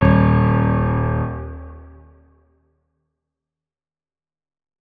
piano
notes-03.ogg